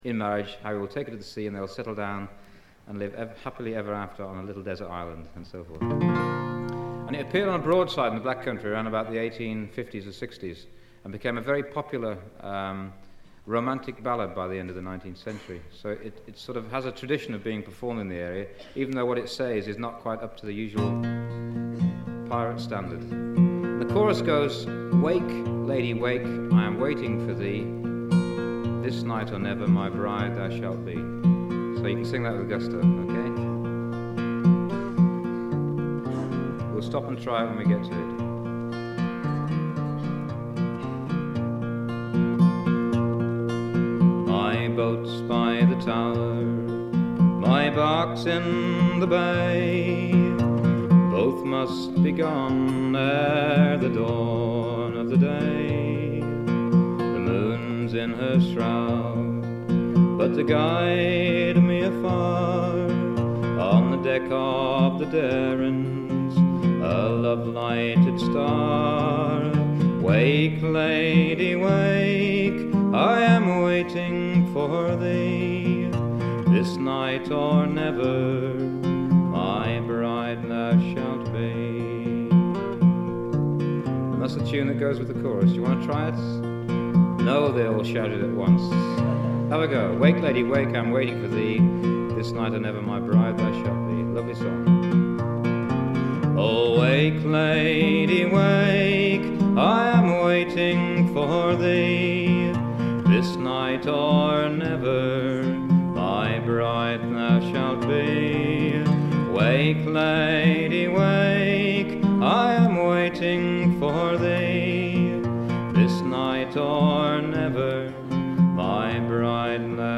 試聴曲は現品からの取り込み音源です。
Comedy, monologues and folk music.